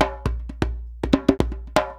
120 JEMBE3.wav